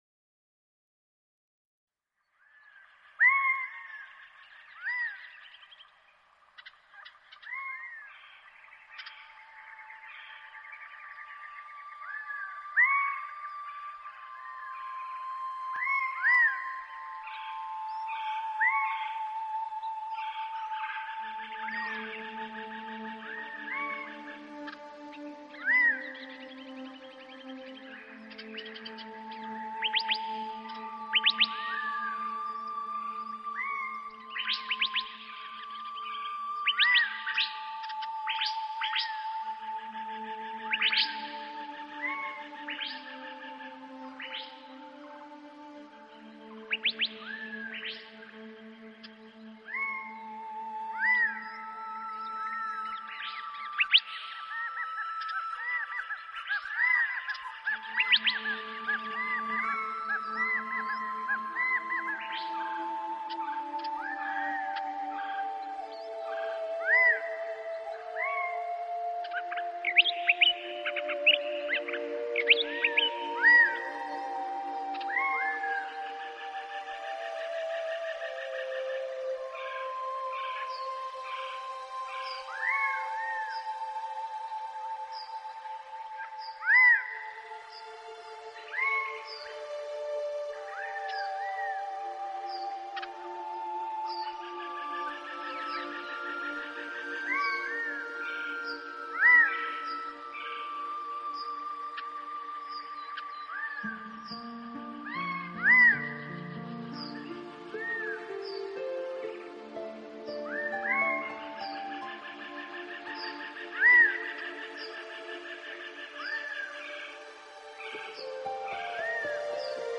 自然聲響與音樂的完美對話
海浪、流水、鳥鳴，風吹過樹葉，雨打在屋頂，
大自然的原始採樣加上改編的著名樂曲合成了天籟之音。